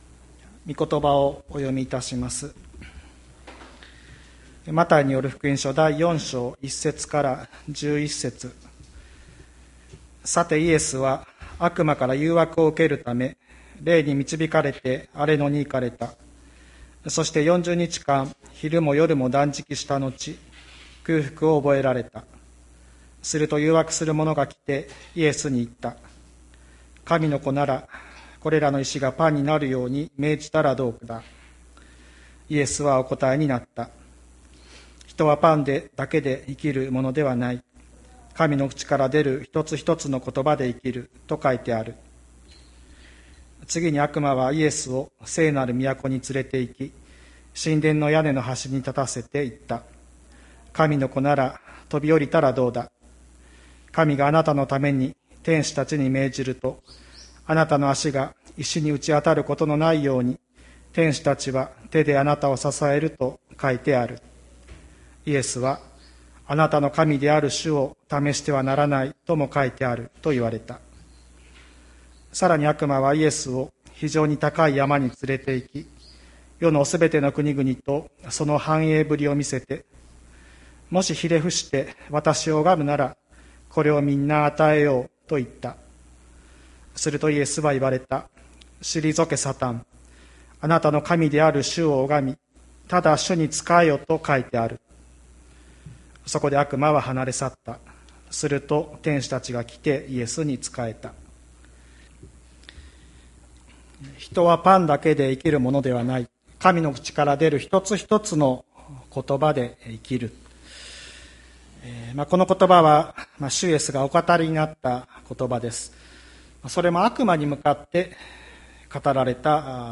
2021年10月03日朝の礼拝「何によって生きるのか」吹田市千里山のキリスト教会
千里山教会 2021年10月03日の礼拝メッセージ。